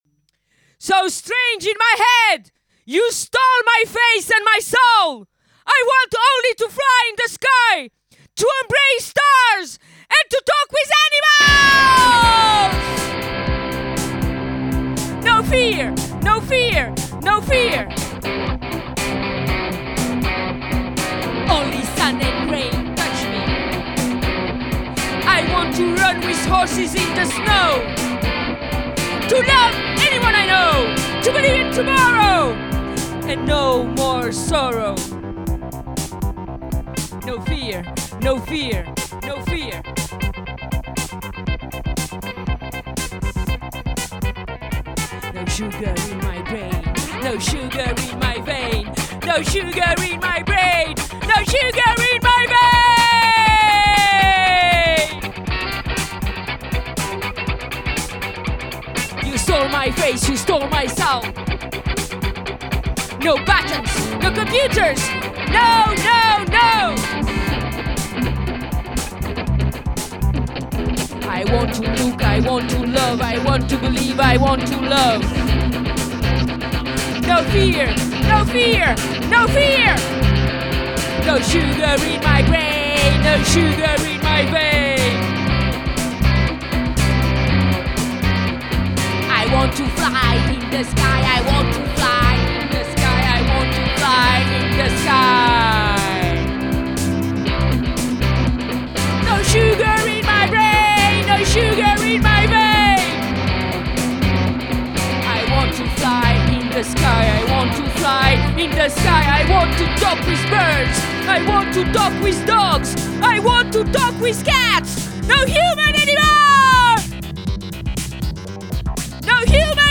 Ces enregistrements de répétition mixés en urgence